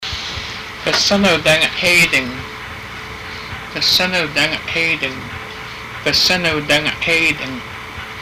Master Fluent Speaker